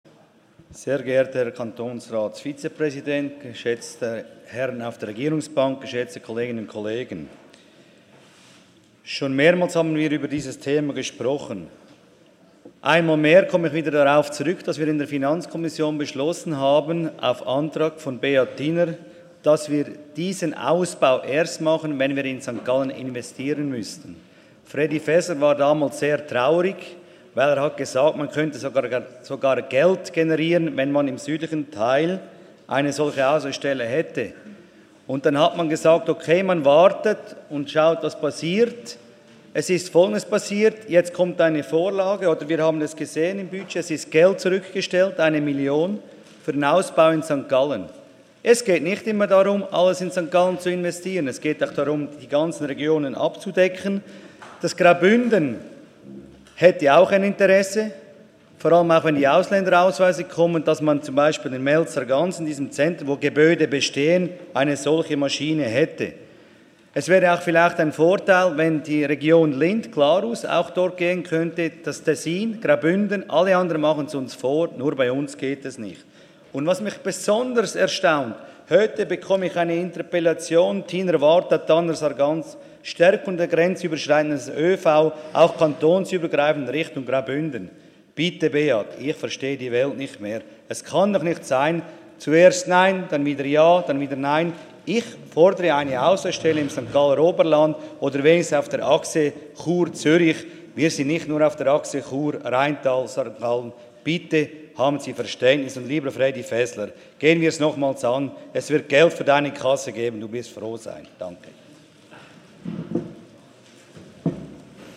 Session des Kantonsrates vom 16. bis 18. September 2019